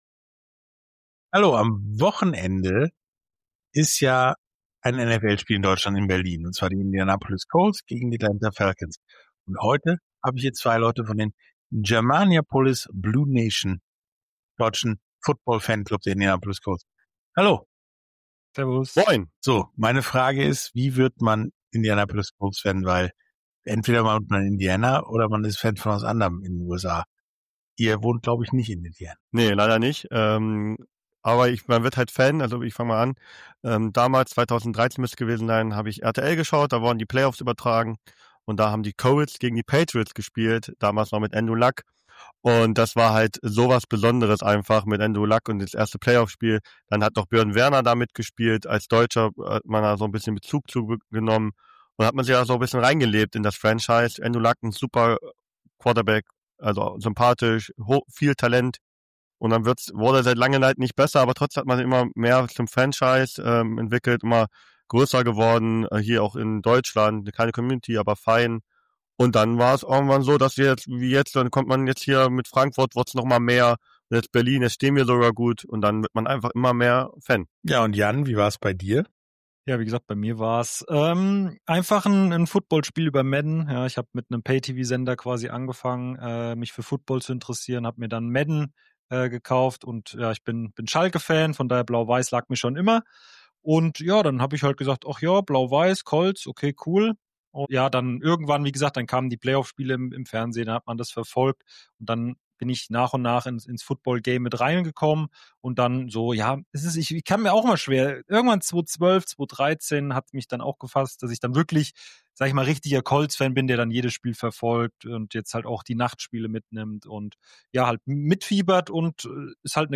Germaniapolis Blue Nation Fanclub Indianapolis Colts ~ Sportstunde - Interviews in voller Länge Podcast